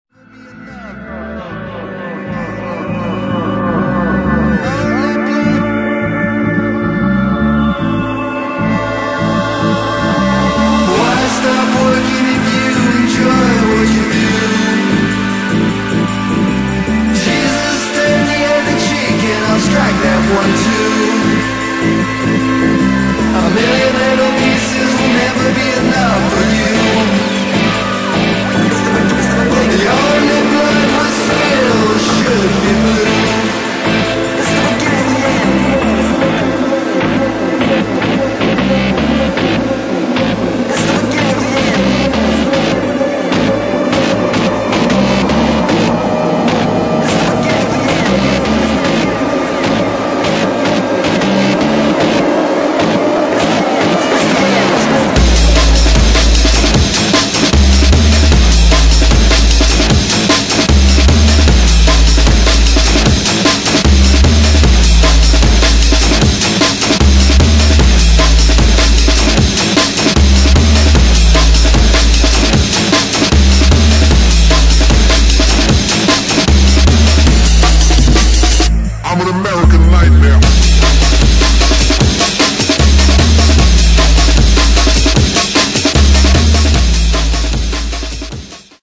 [ ROCK / JUNGLE ]
ドラムンベース・マスター
B面にアーメン・ジャングル・リミックス収録！！